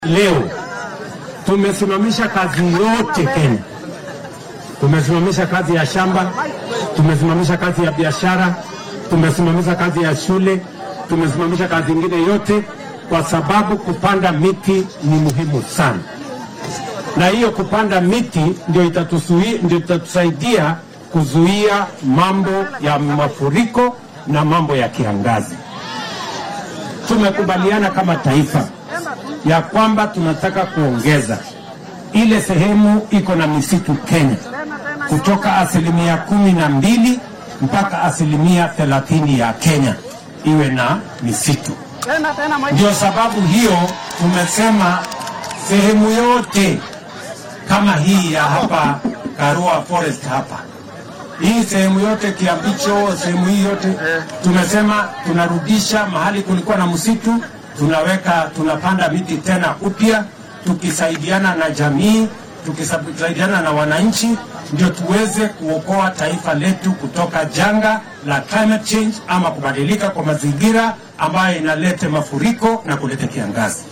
DHAGFYSO:Madaxweynaha dalka oo faahfaahiyay ujeeddada maanta geedaha loo beeraya
Madaxweynaha dalka William Ruto ayaa maanta kenyaanka ku hoggaamiyay munaasabad lagu beeraya ugu yaraan 500 oo milyan oo geedo ah. Xilli uu ku sugnaa kaynta Kiambicho Forest ee ismaamulka Murang’a ayuu sheegay in ujeeddada arrintan laga leeyahay ay tahay in looga gaashaanto saameynta isbeddelka cimillada.